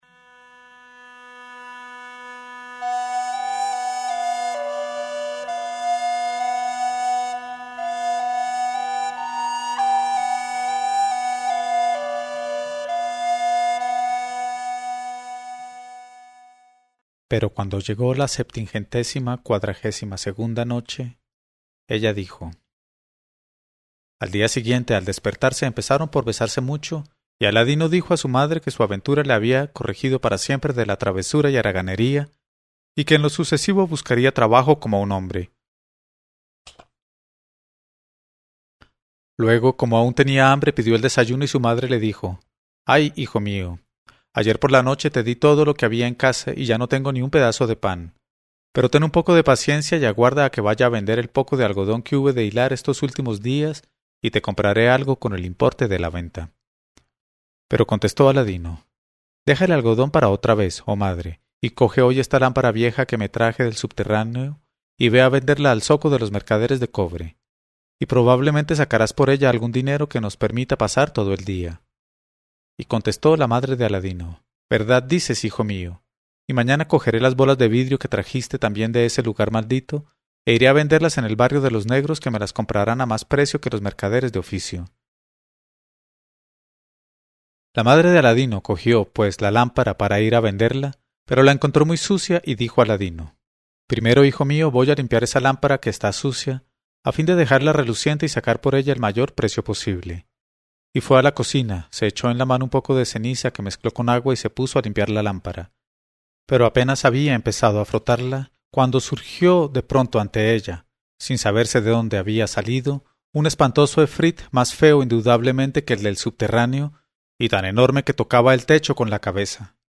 Una lectura en voz alta de Las mil noches y una noche.